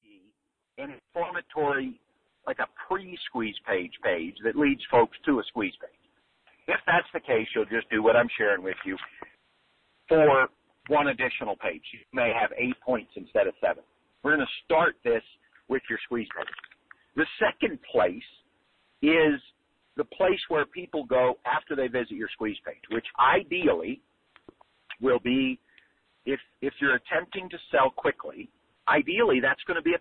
This is an audio course